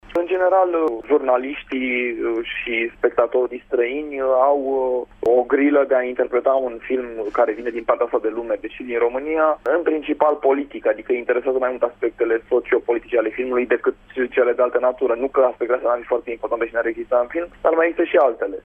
Publicul din străinătate cel mai mult a fost interesat de aspectele socio-politice ale peliculei, a spus Radu Jude, intervievat prin telefon în emisiunea Sens Unic la Radio Tîrgu-Mureș: